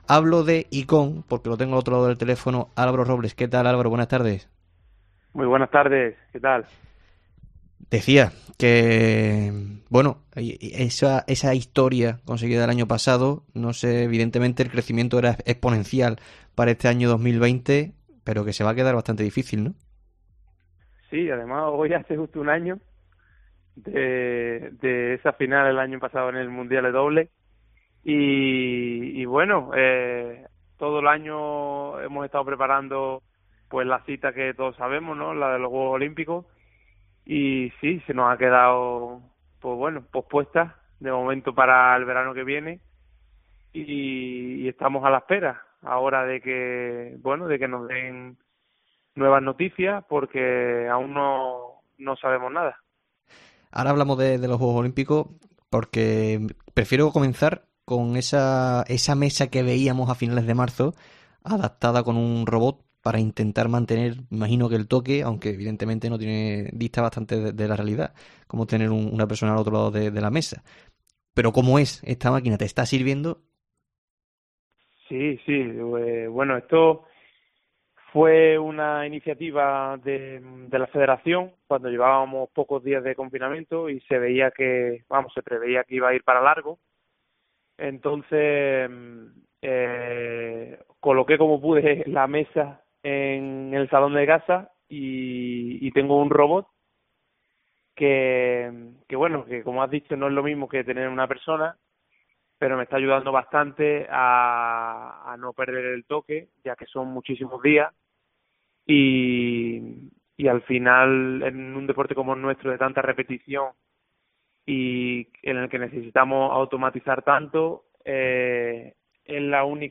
En el tiempo local de Deportes COPE hablamos con Álvaro Robles justo el día que celebra un año del subcampeontao del mundo de Budapest conseguido en 2019.